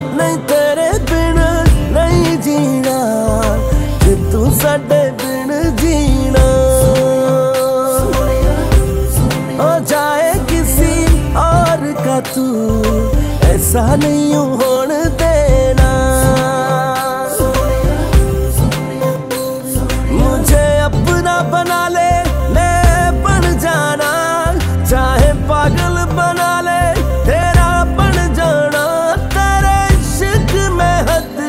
Hindi Songs
• Simple and Lofi sound
• Crisp and clear sound